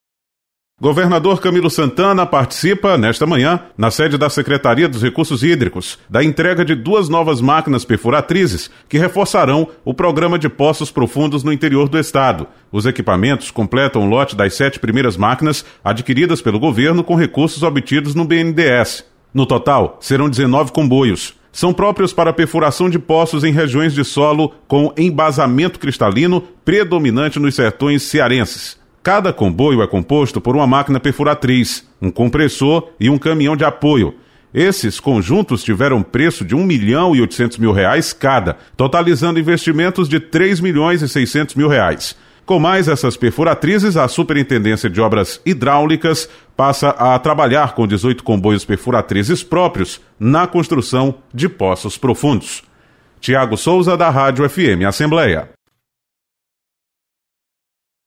Governo do Estado entrega perfuratrizes para reforço do programa de poços profundos no Interior do Ceará. Repórter